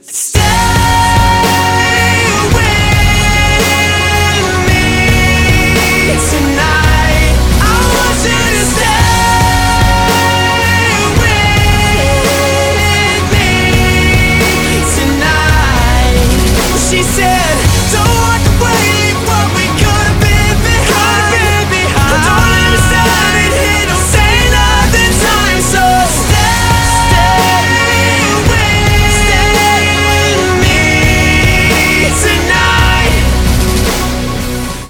• Качество: 192, Stereo
рок
Романтическая рок-композиция